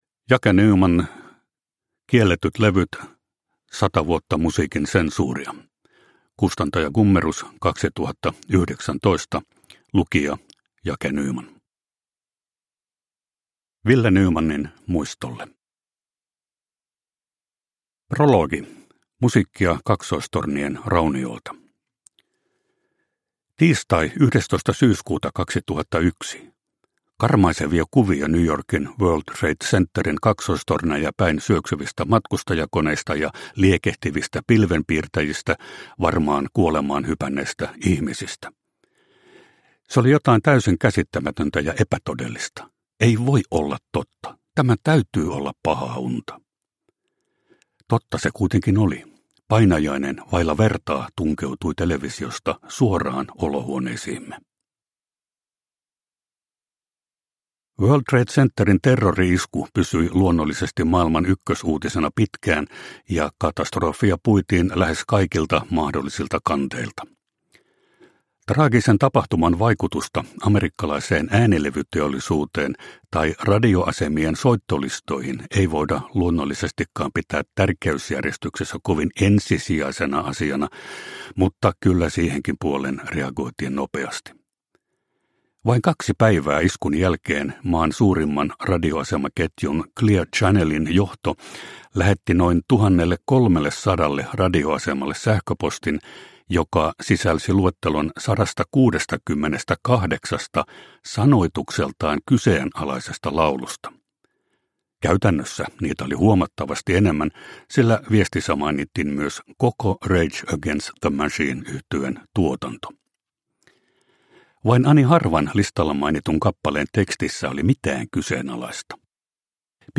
Kielletyt levyt – Ljudbok – Laddas ner